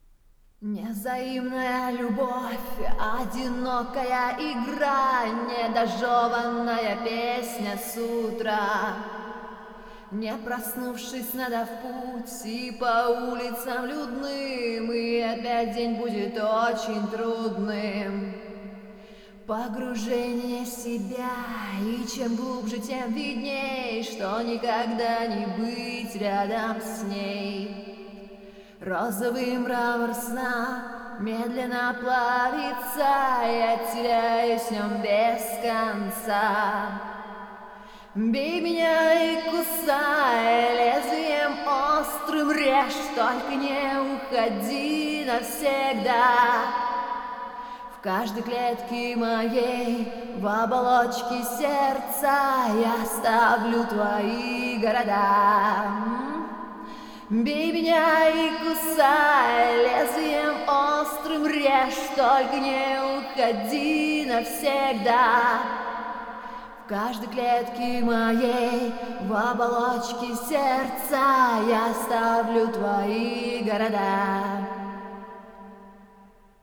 Профессиональный диктор, осуществляю качественную запись ваших проектов приятным голосом на русском, английском и французском языках.
Сейчас вокалист: